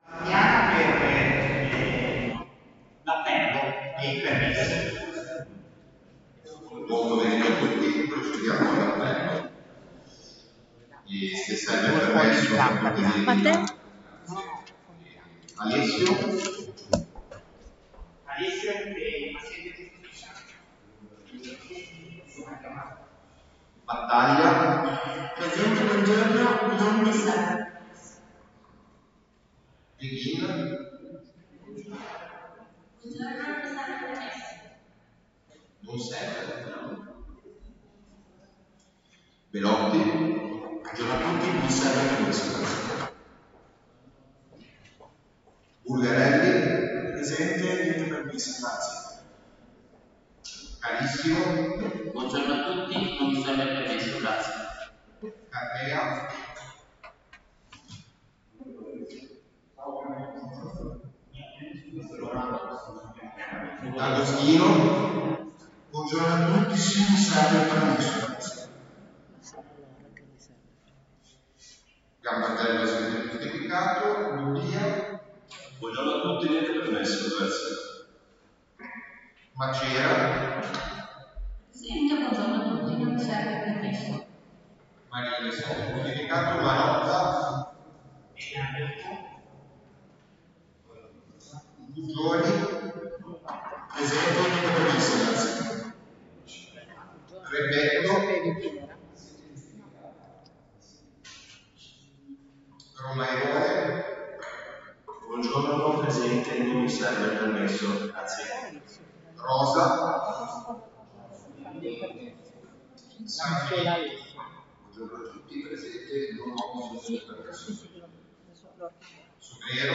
seduta_consiglio_del_19_giugno.mp3